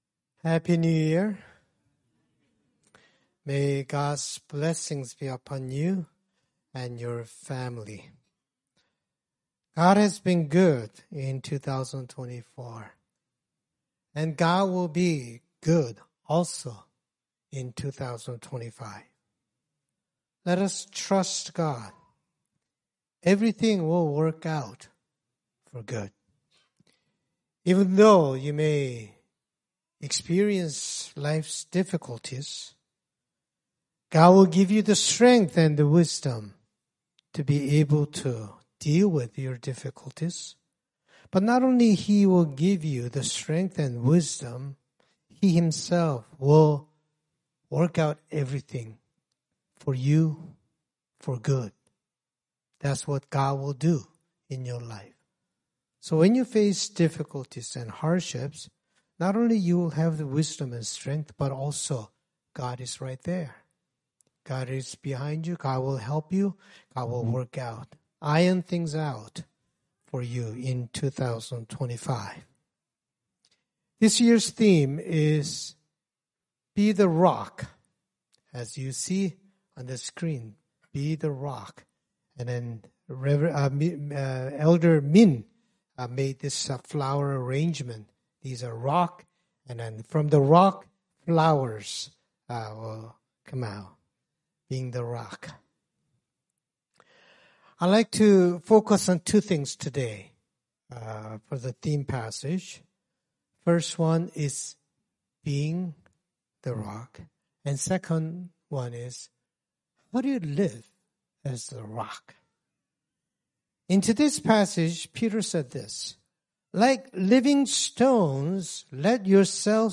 Scripture Passage 1 Peter 2:4-10 Worship Video Worship Audio Sermon Script Happy New Year!